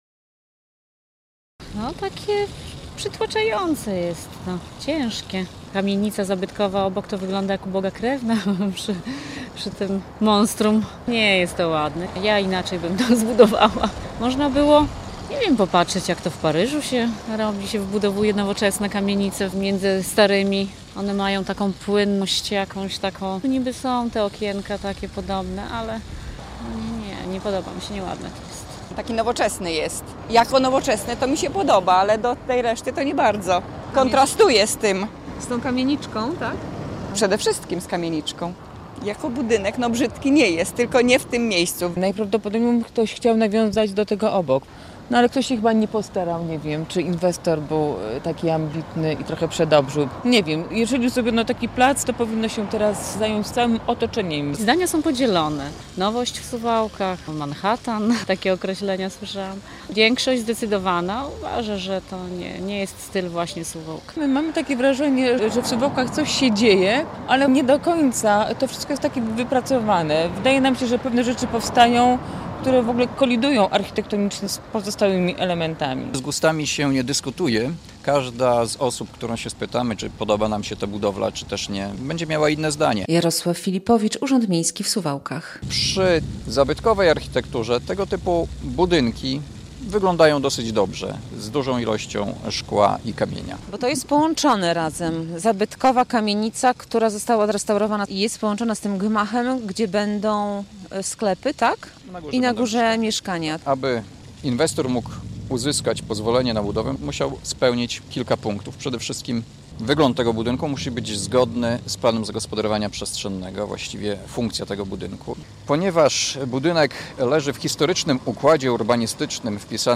Kontrowersyjna zabudowa w sąsiedztwie zabytkowych kamieniczek - relacja